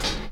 Closed Hat (Barry Bonds).wav